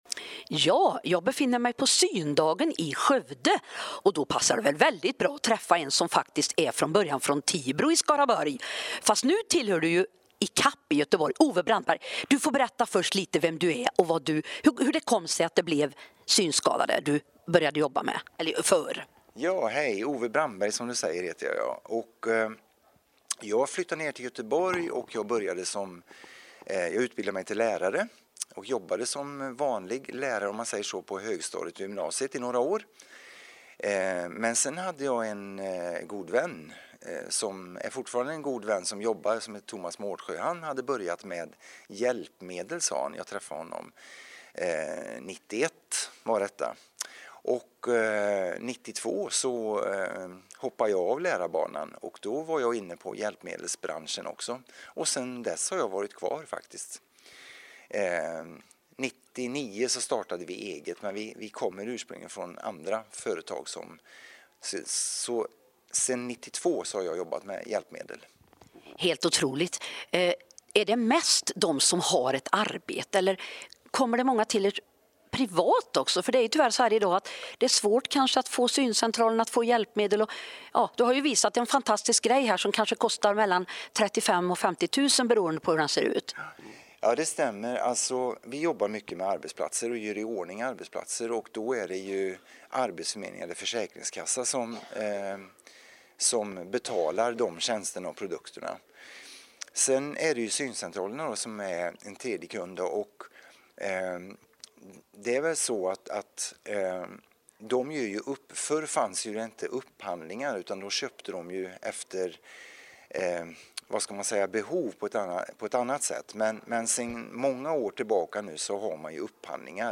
Två foton  på oss (ett sittande och ett stående) på Syndagen i Skövde
Fantastisk intervju om olika hjälpmedel.